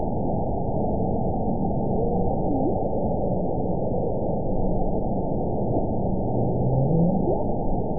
event 922112 date 12/26/24 time 13:30:02 GMT (11 months, 1 week ago) score 7.81 location TSS-AB04 detected by nrw target species NRW annotations +NRW Spectrogram: Frequency (kHz) vs. Time (s) audio not available .wav